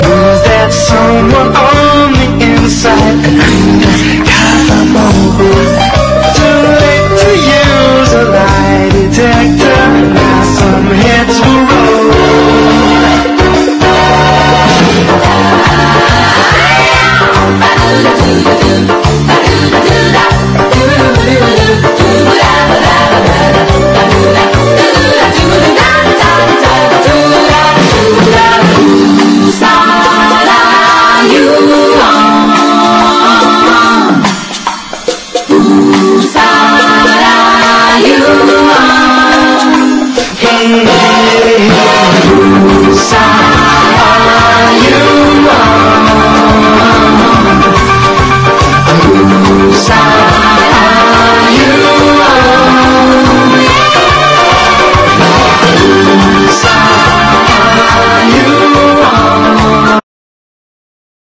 FUNKY SOUL
ファンキー・ゴスペル！